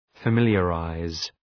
Shkrimi fonetik {fə’mıljə,raız}